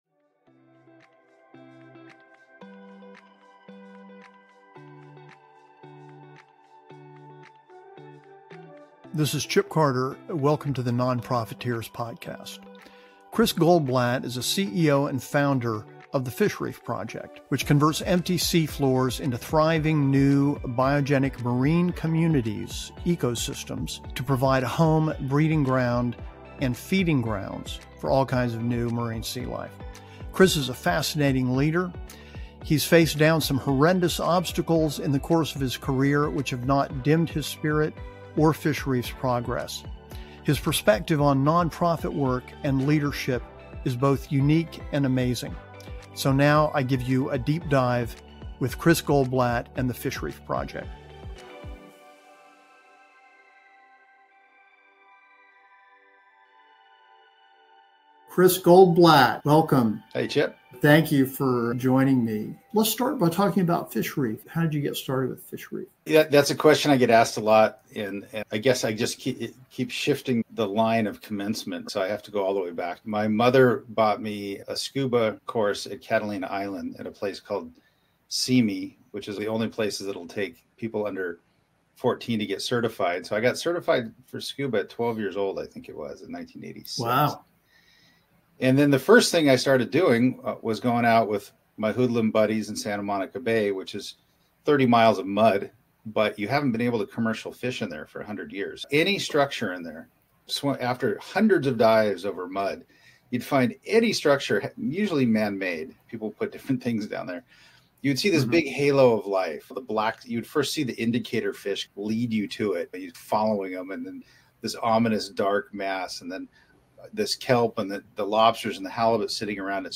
Interview Transcript